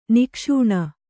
♪ nikṣūṇa